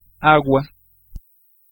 Ääntäminen
IPA : /pɒp/